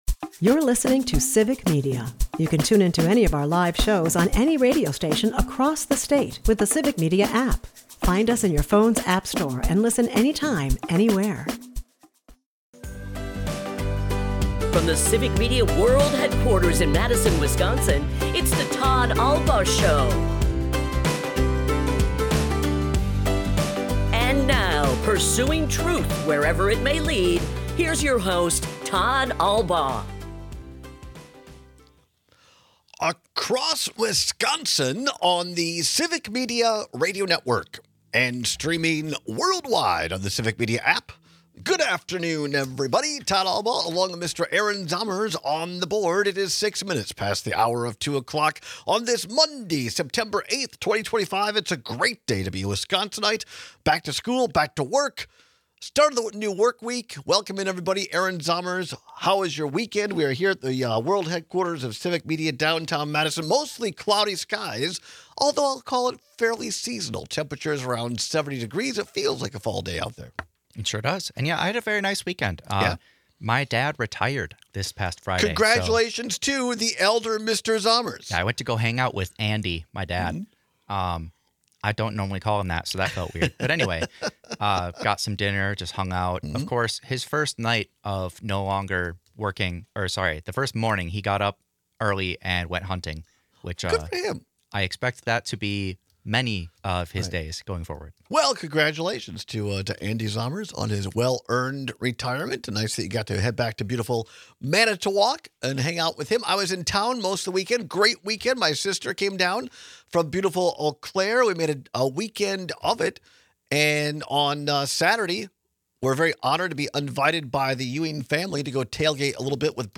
The Badgers, Brewers, and Packers all brought home wins. We share some reactions from Micah Parsons, Jordan Love, and Matt LeFleur from the Packers before moving on to Pat Murphy from the Brewers.&nbsp